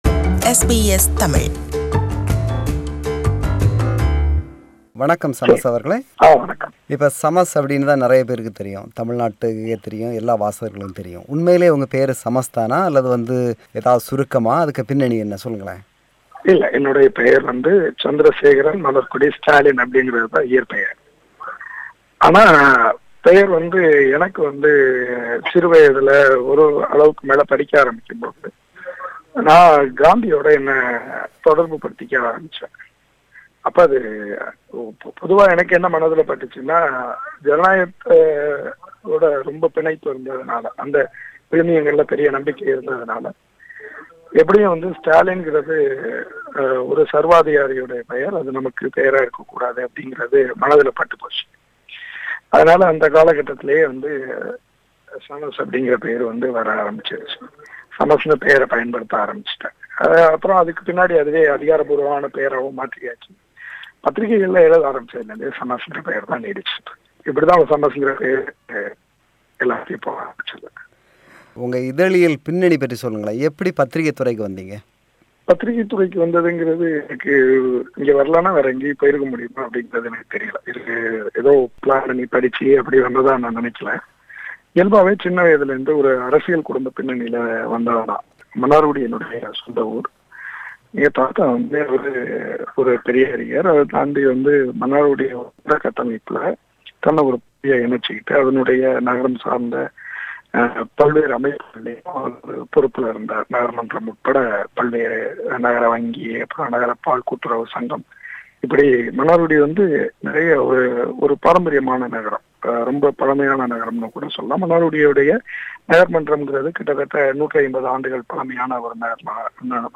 Interview – Part 1.